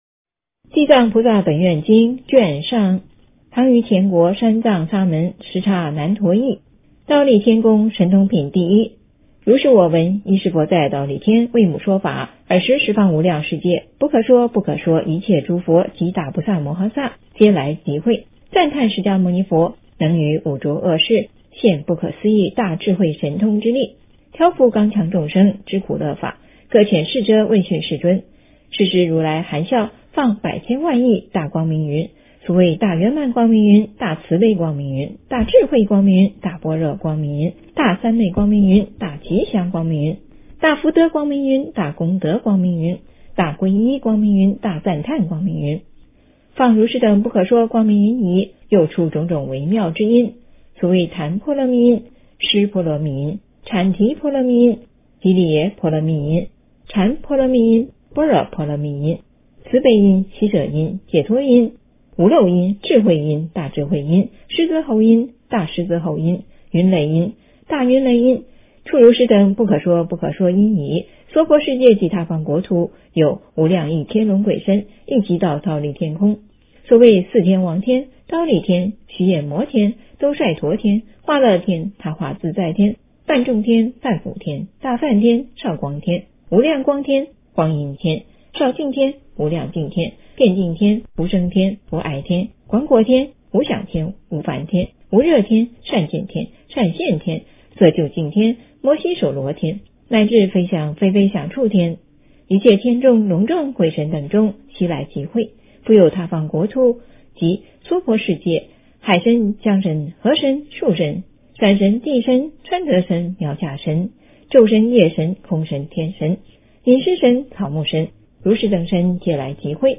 地藏经-忉利天宫神通品第一 - 诵经 - 云佛论坛